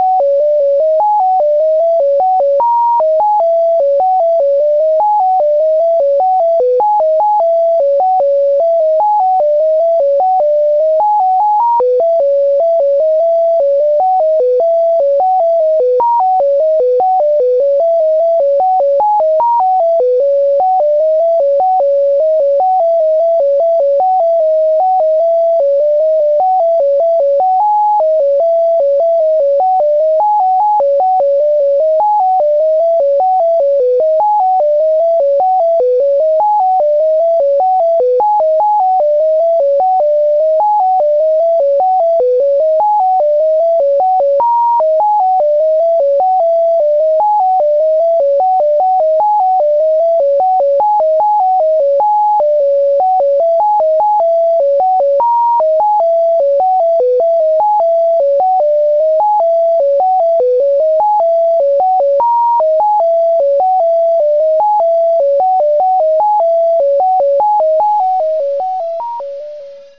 the first has a note mapping that includes an Eb, and